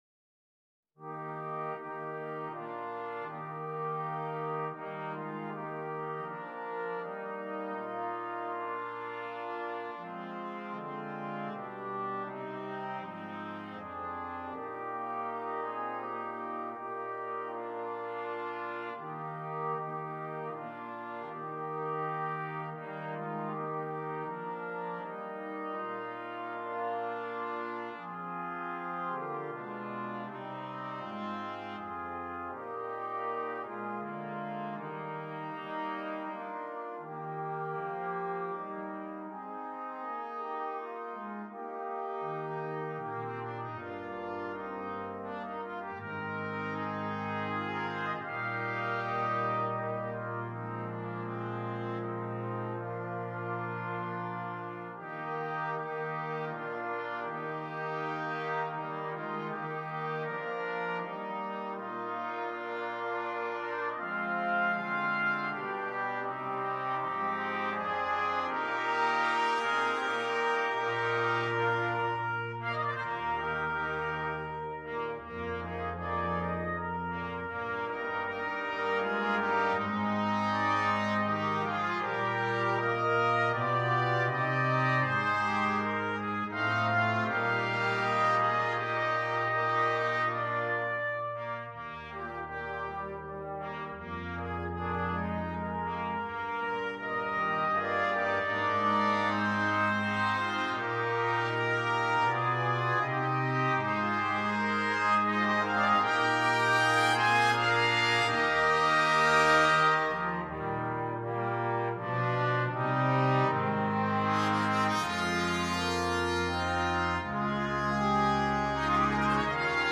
Besetzung: Brass Sextet